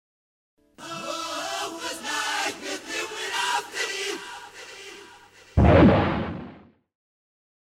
Genere: heavy metal
Incomprensibile